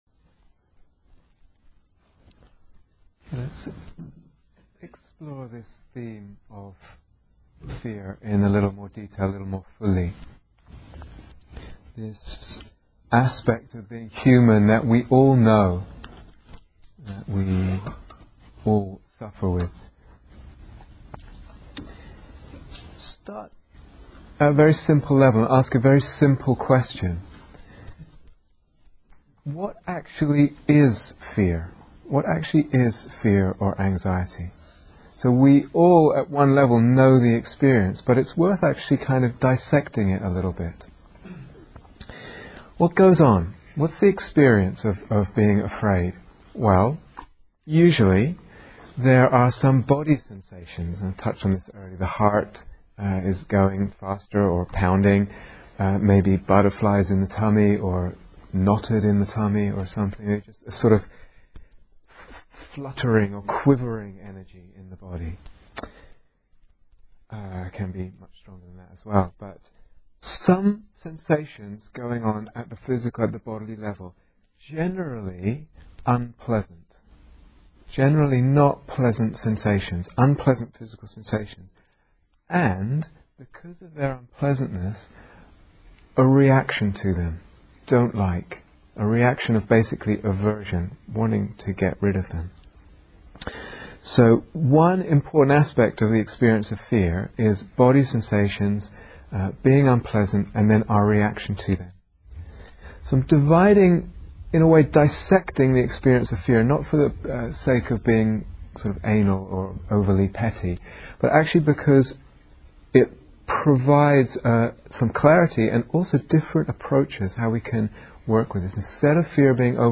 Day Retreat, London Insight 2008